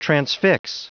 Prononciation du mot transfix en anglais (fichier audio)
Prononciation du mot : transfix